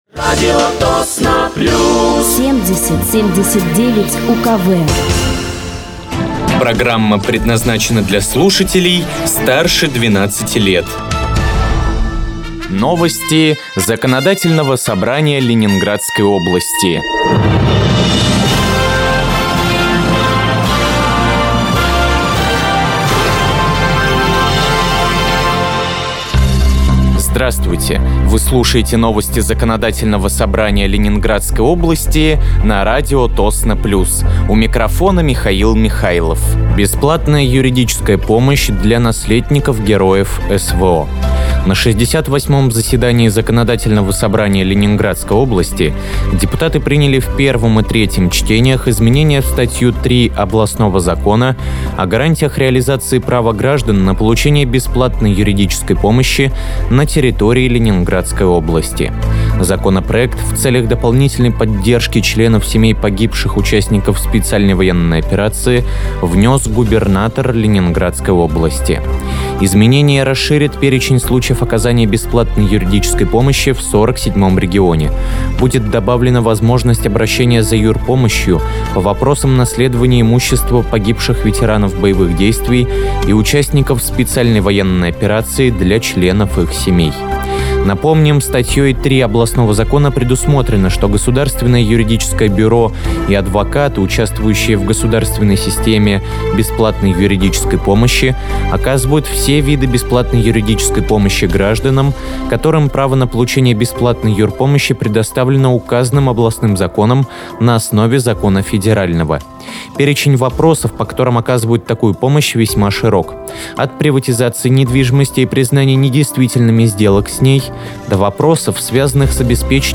Выпуск новостей Законодательного собрания Ленинградской области от 25.09.2025
Вы слушаете новости Законодательного собрания Ленинградской области на радиоканале «Радио Тосно плюс».